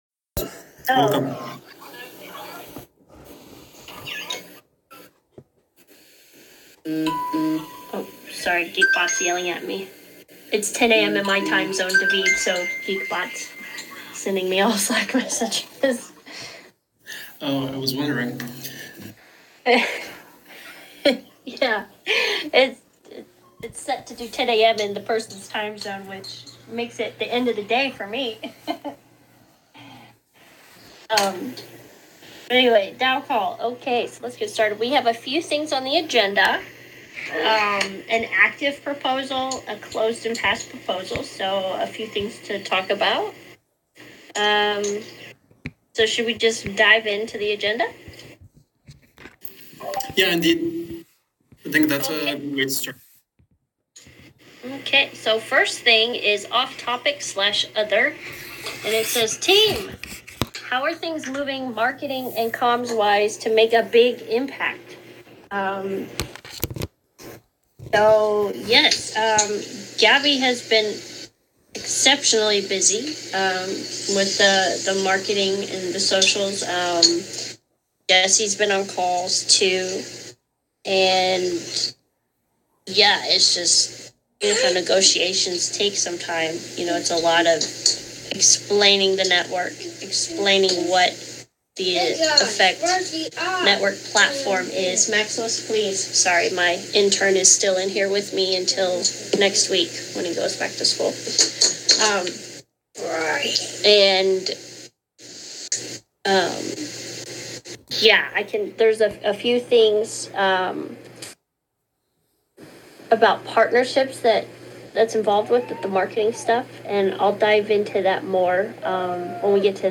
Source: Discord